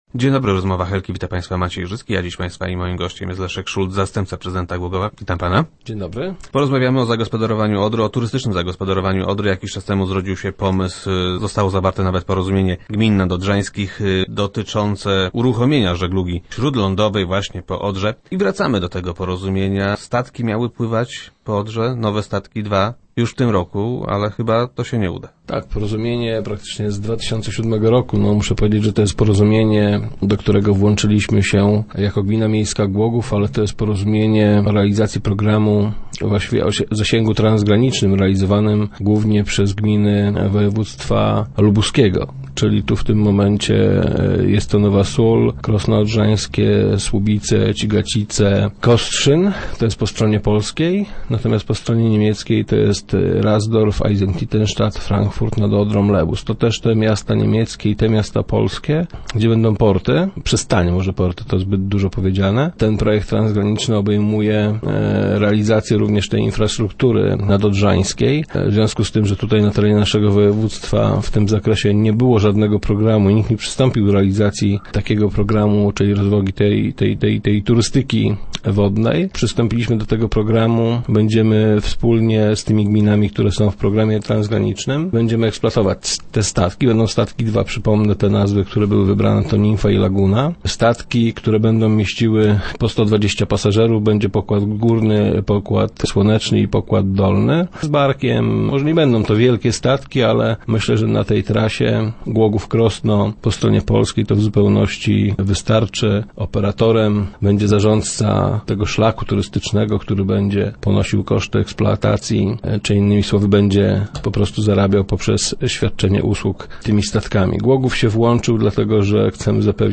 Myślę, że do tego czasu my przygotujemy przystań, do której będą mogły one przybijać - powiedział nam wiceprezydent Leszek Szulc, który był gościem Rozmów Elki.